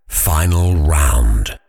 TowerDefense/Assets/Audio/SFX/Voiceover/final_round.ogg at ad5d9b8d825c62997e33d86ccaf30de963ff1904
final_round.ogg